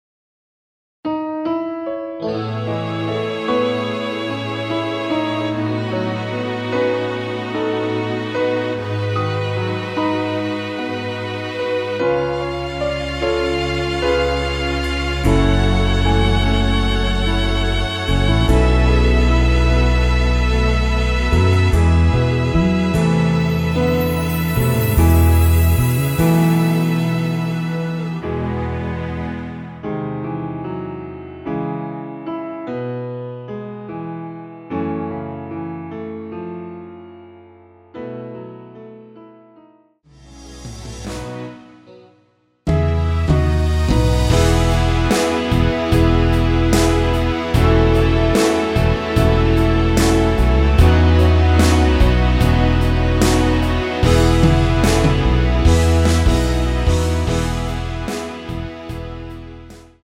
(-3)내린 MR 입니다.
◈ 곡명 옆 (-1)은 반음 내림, (+1)은 반음 올림 입니다.
앞부분30초, 뒷부분30초씩 편집해서 올려 드리고 있습니다.